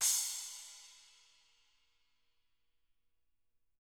Index of /90_sSampleCDs/ILIO - Double Platinum Drums 1/CD4/Partition H/SPLASH CYMSD